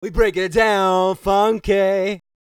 TB SING 301.wav